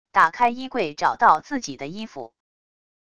打开衣柜找到自己的衣服wav音频